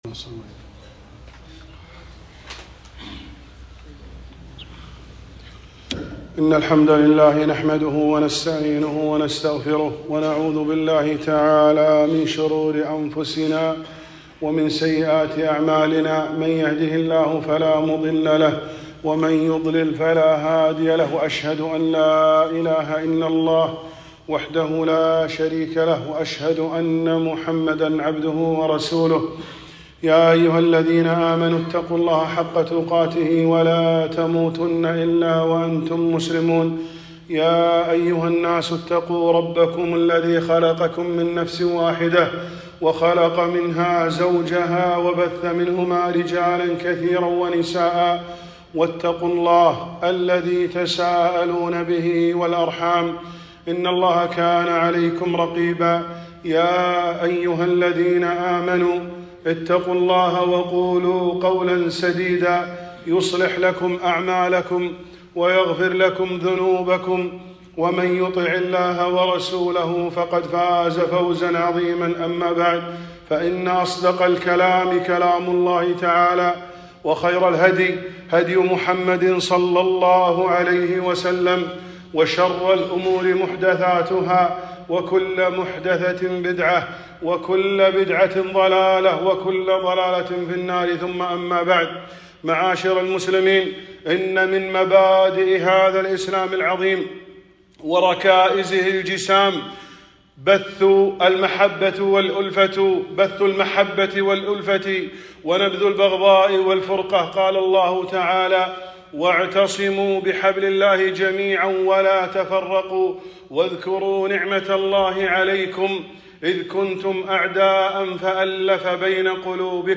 خطبة - صلة الرحم